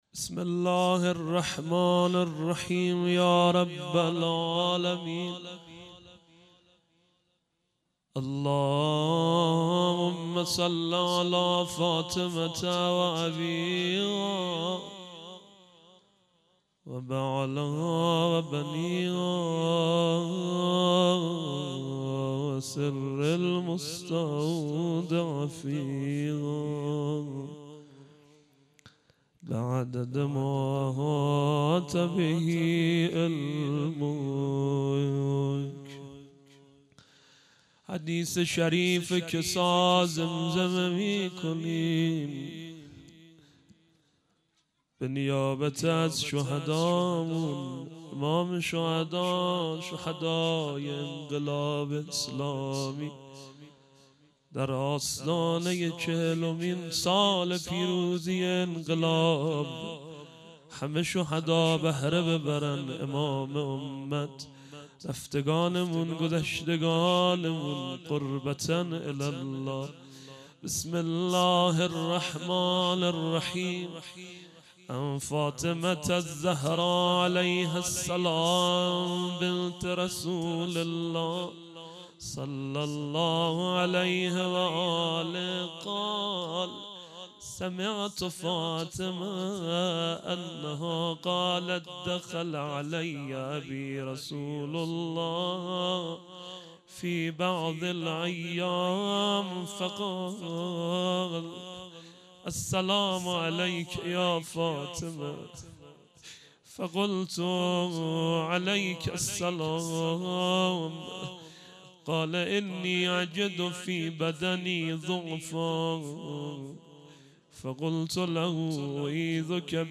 فاطمیه97- مجمع دلسوختگان بقیع- شب چهارم- قرائت حدیث شریف کساء